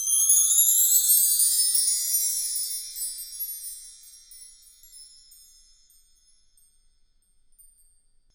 Index of /90_sSampleCDs/Roland LCDP03 Orchestral Perc/PRC_Wind Chimes1/PRC_W.Chime Up